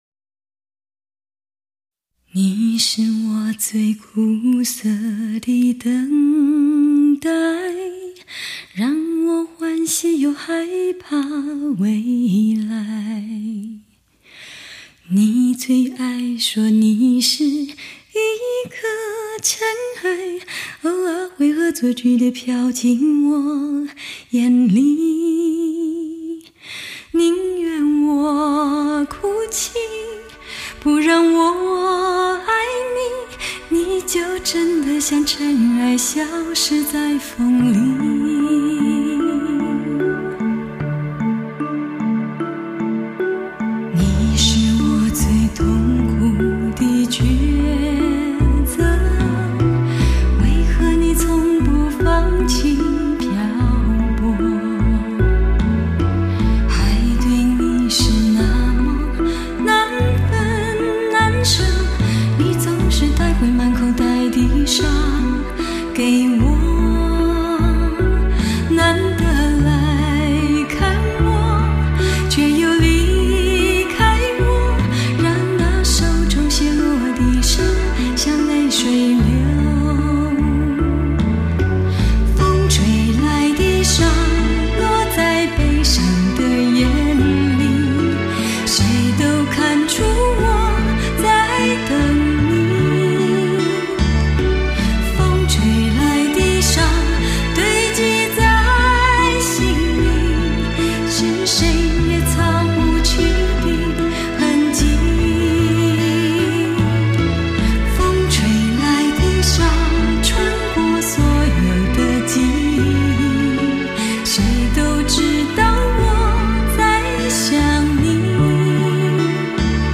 母盘德国直刻无信号衰减技术
MADE IN GERMANY 限量版 德国录音
一把近乎完美的声音，加上精挑细选的旋律，创新的编曲和无可挑剔的录音技术，等待你细细品味，反复聆听。